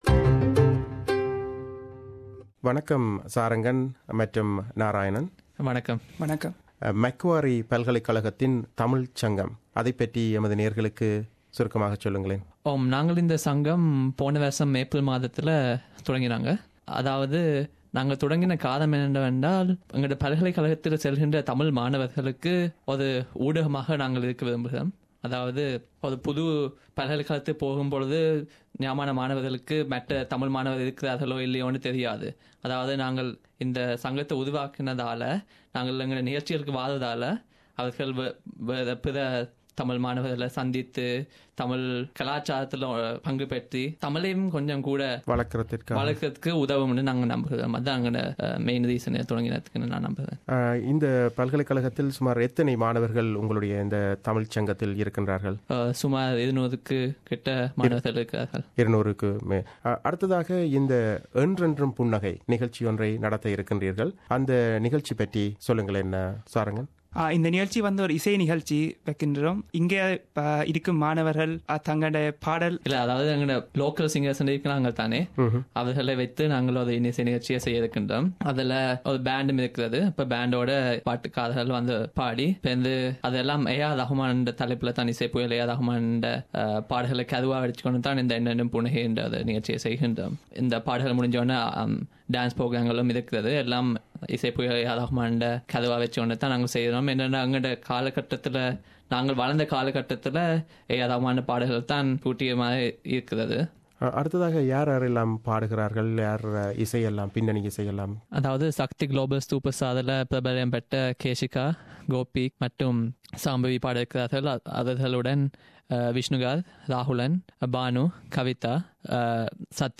SBS கலையகம் வந்து